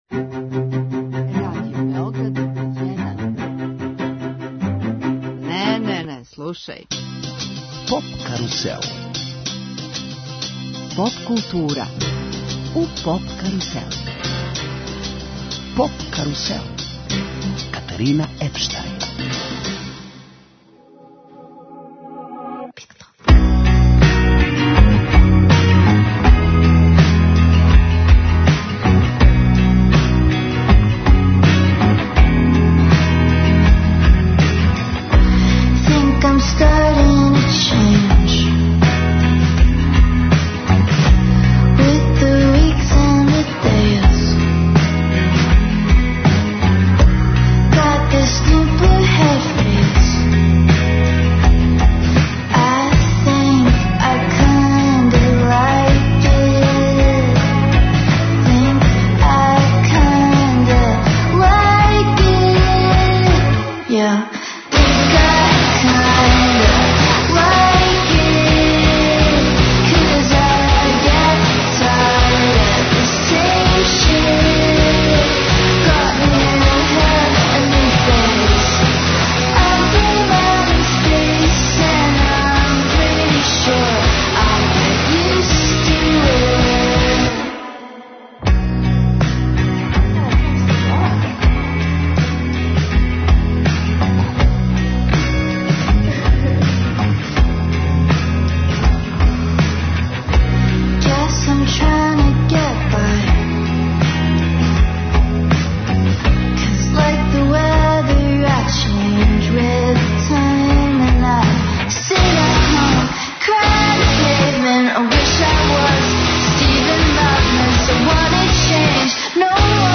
Ексклузивно, емитујемо интервју са једном од најпопуларнијих светских певачица, представницом нове диско поп сцене, Minelli.